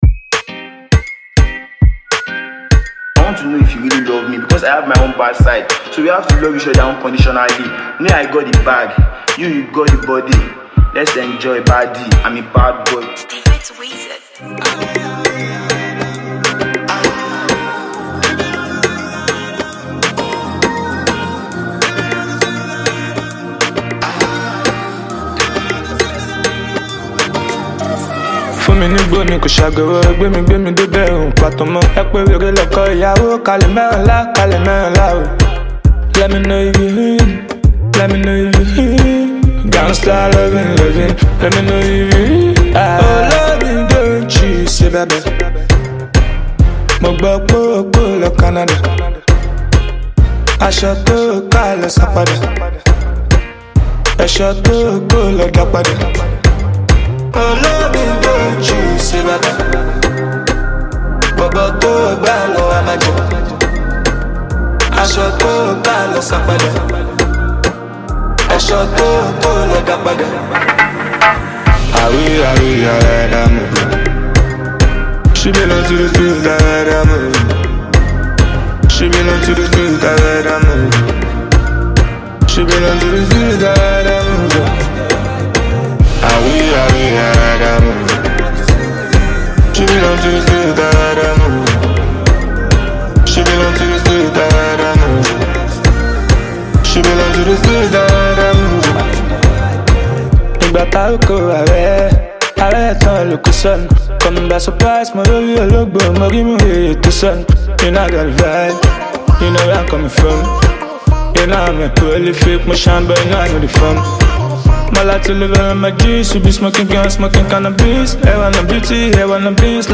Phenomenal talented Nigerian rap artiste